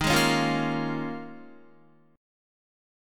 D#9sus4 chord